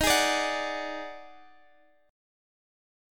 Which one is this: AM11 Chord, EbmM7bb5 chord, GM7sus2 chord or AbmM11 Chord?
EbmM7bb5 chord